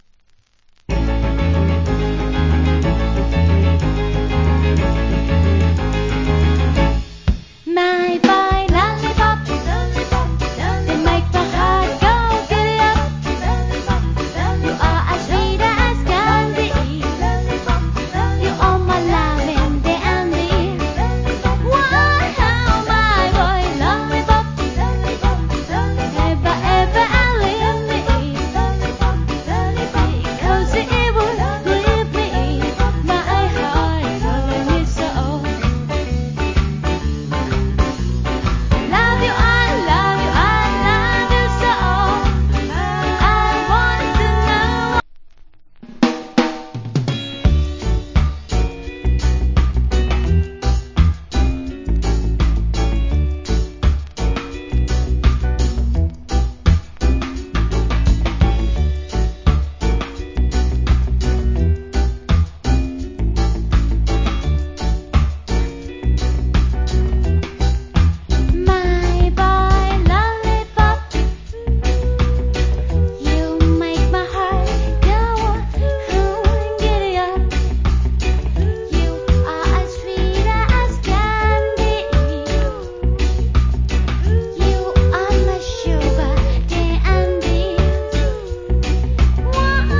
コメント Wicked Female Ska Vocal. / Rock Steady Version.